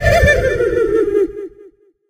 tara_kill_vo_03.ogg